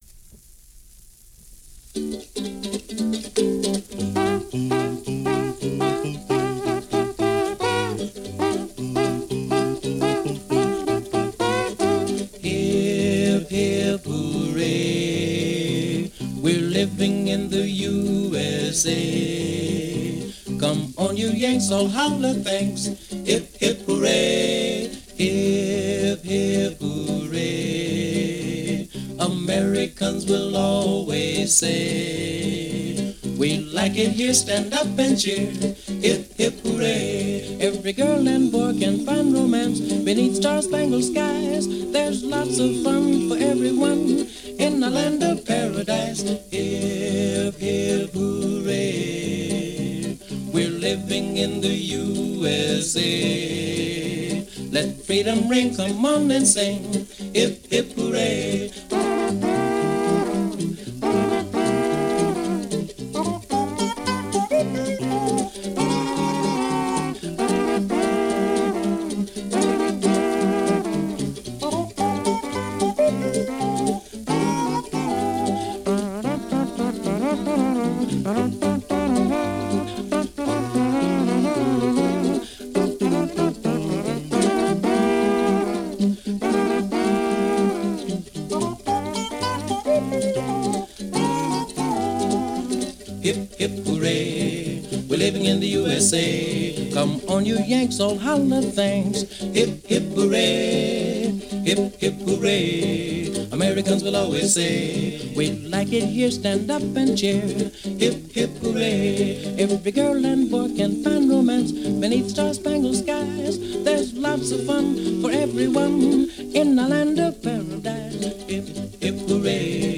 Standard Broadcast Transcription Session
lead singer
baritone
tenor
guitar accompaniment